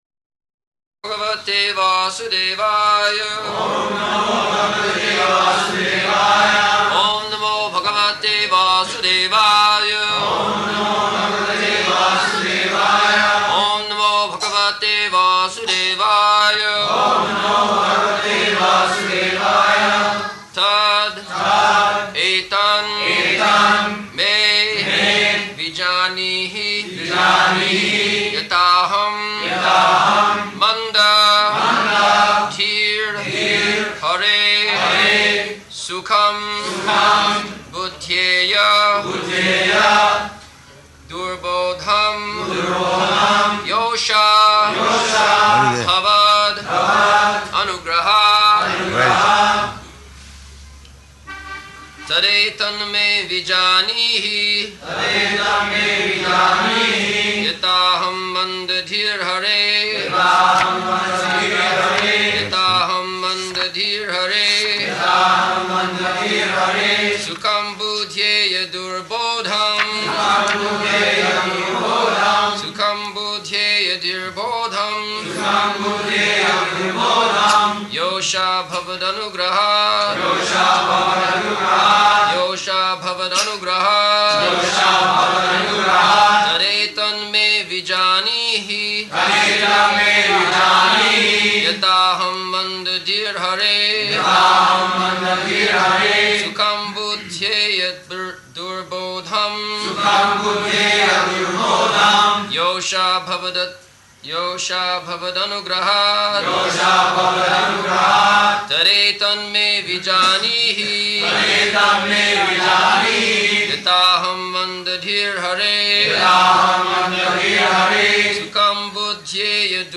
November 30th 1974 Location: Bombay Audio file